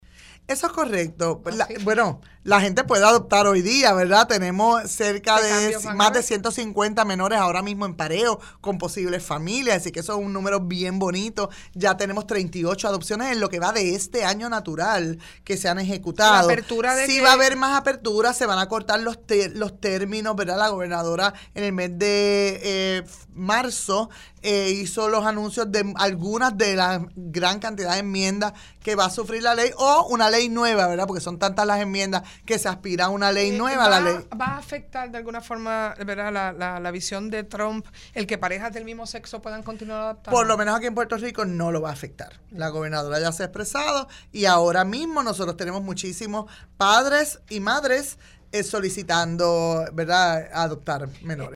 La secretaria del Departamento de la Familia, Suzanne Roig indicó en El Calentón que padres de casi mil menores en Puerto Rico han perdido custodia de sus hijos por maltrato.